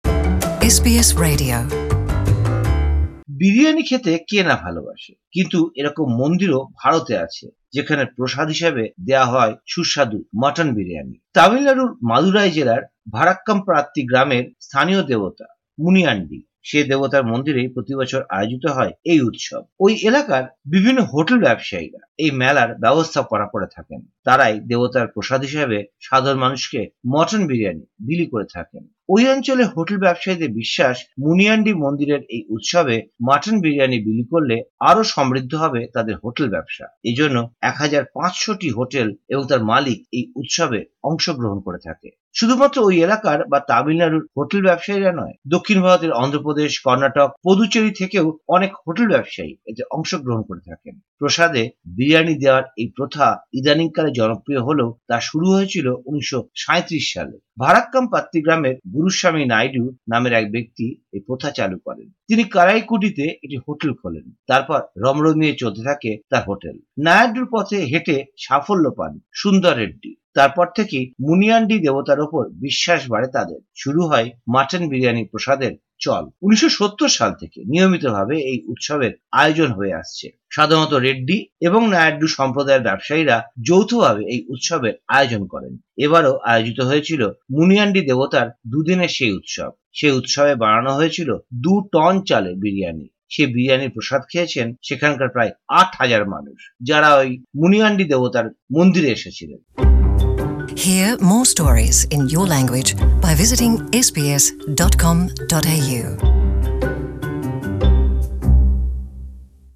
প্রতিবেদনটি বাংলায় শুনতে উপরের অডিও প্লেয়ারটিতে ক্লিক করুন।